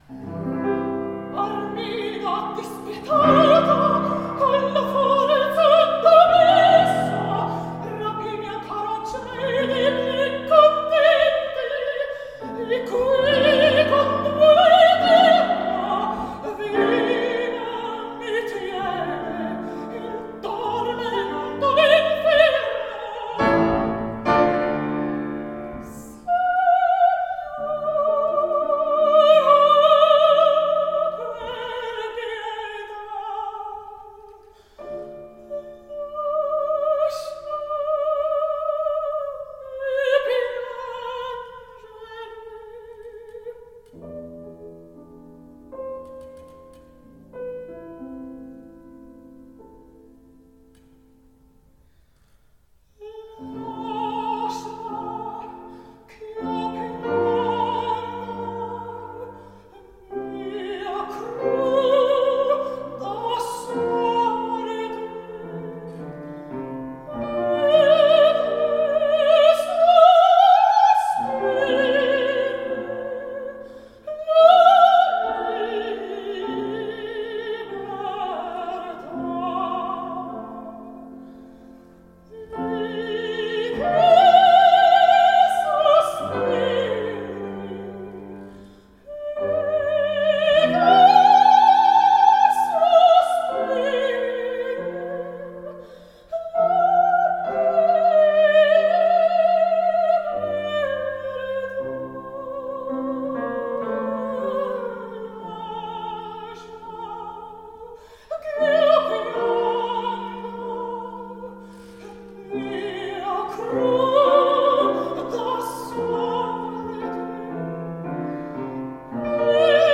Almirena’s aria from George Frideric Handel’s baroque opera Rinaldo.